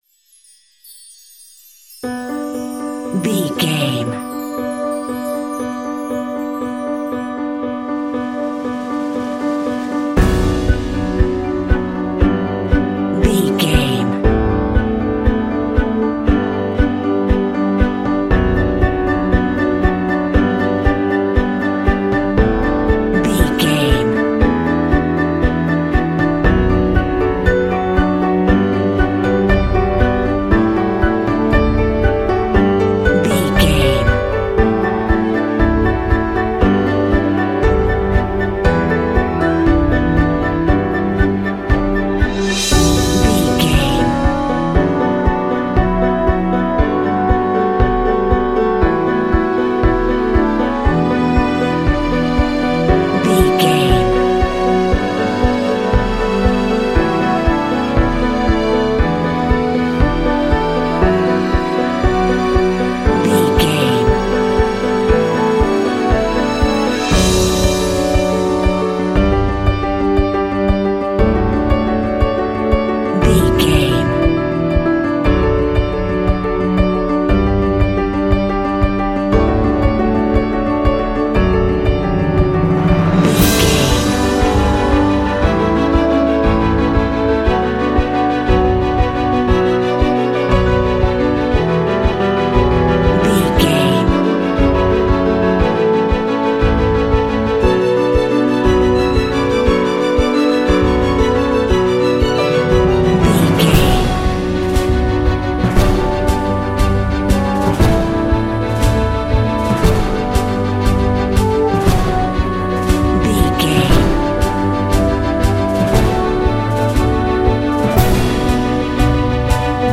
Epic / Action
Aeolian/Minor
tension
driving
dramatic
hopeful
piano
strings
orchestra
contemporary underscore